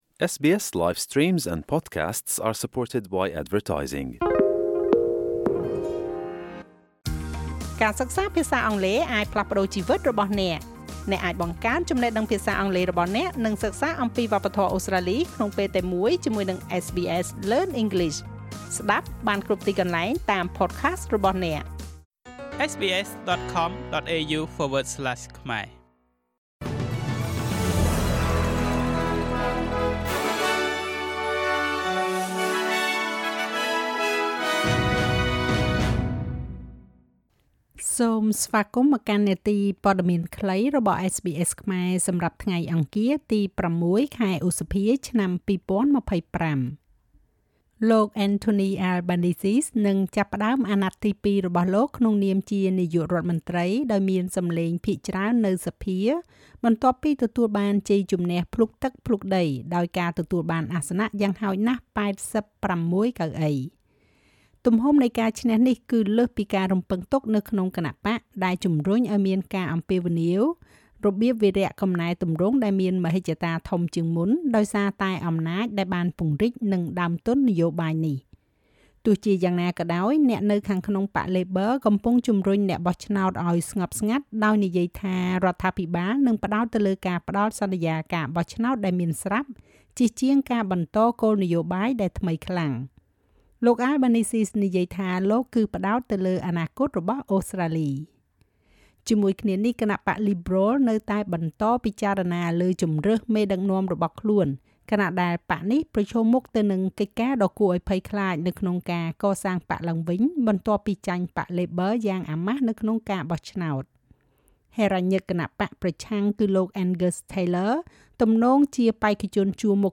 នាទីព័ត៌មានខ្លីរបស់SBSខ្មែរ សម្រាប់ថ្ងៃអង្គារ ទី៦ ខែឧសភា ឆ្នាំ២០២៥